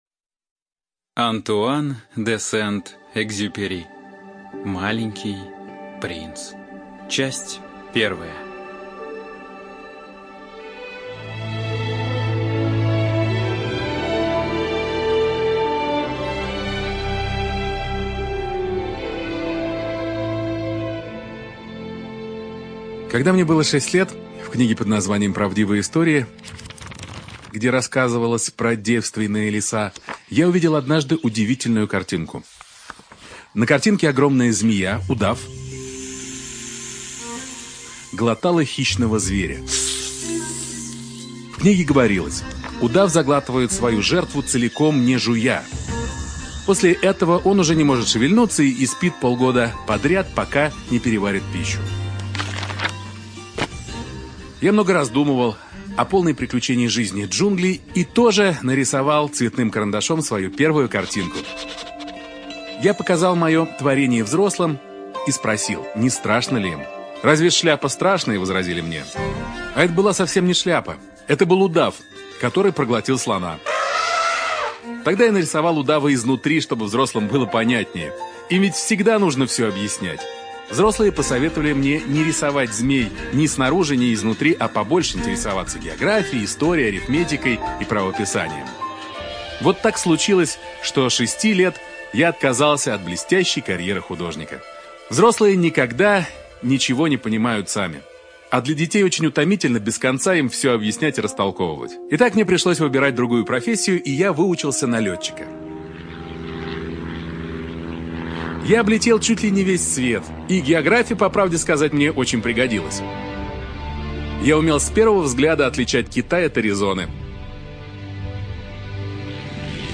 ЖанрРадиоспектакли
Студия звукозаписиРадио России Нижний Новгород